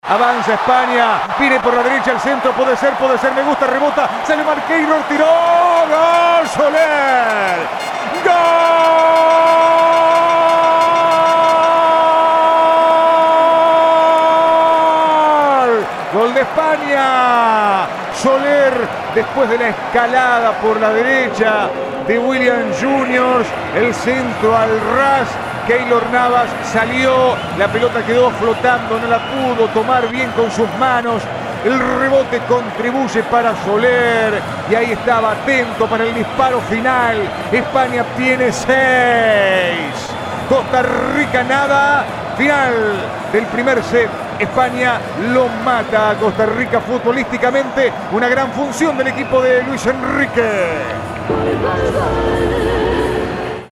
Audio. 6º gol de España vs Costa Rica - Soler (relato